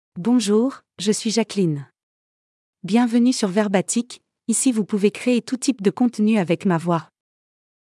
Jacqueline — Female French (France) AI Voice | TTS, Voice Cloning & Video | Verbatik AI
Jacqueline is a female AI voice for French (France).
Voice sample
Female